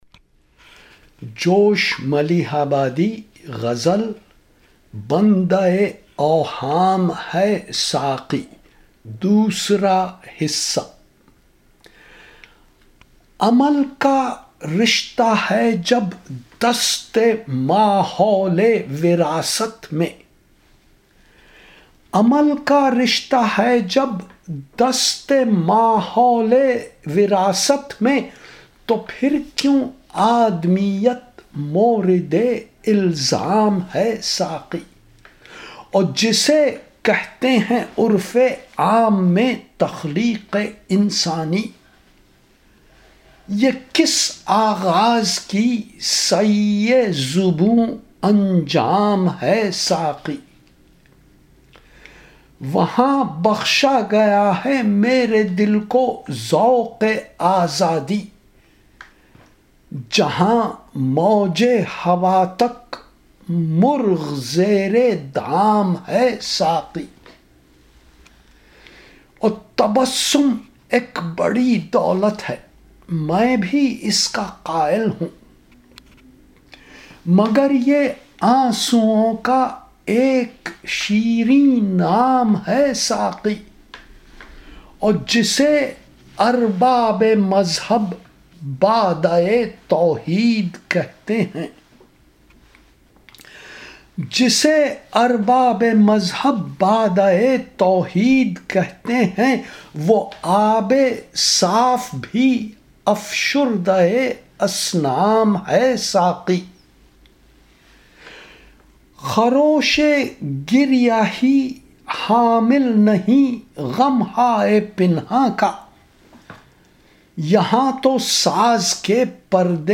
Recitation